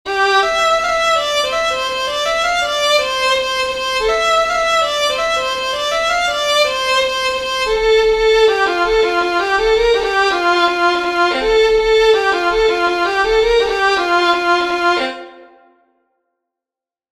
Musiche digitali in mp3 tratte dagli spartiti pubblicati su
Raccolta e trascrizioni di musiche popolari resiane